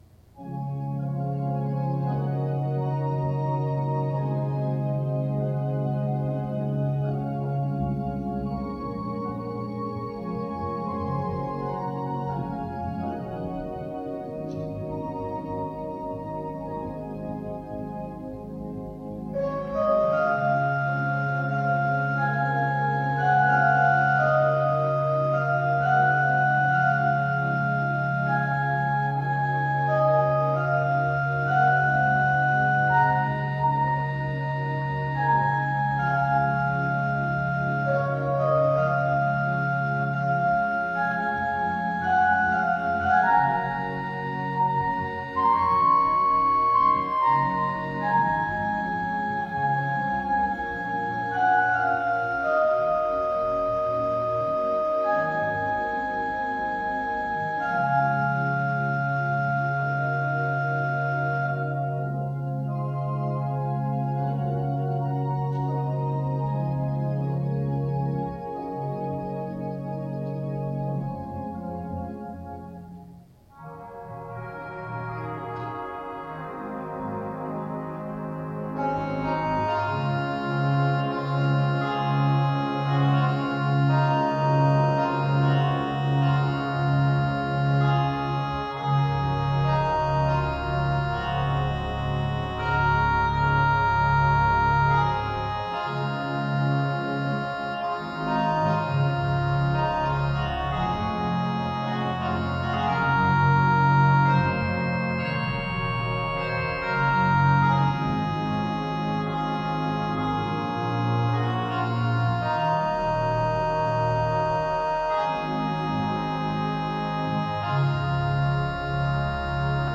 By Organist/Pianist